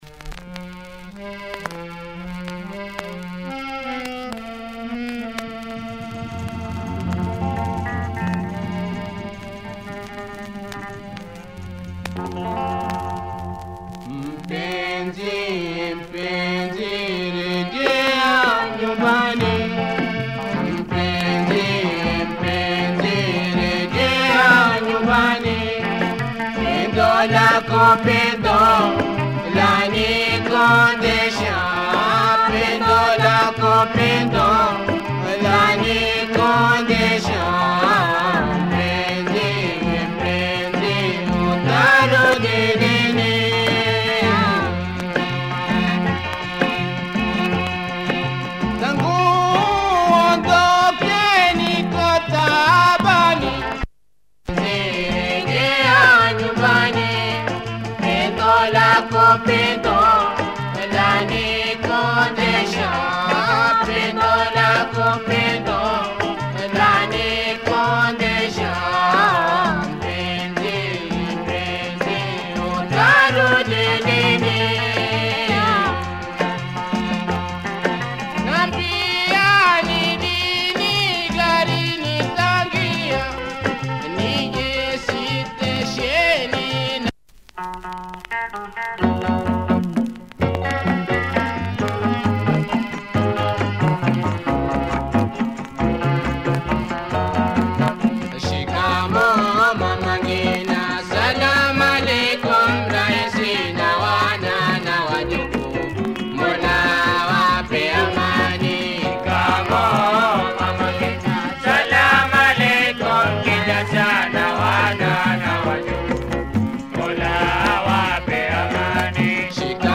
Nice taarab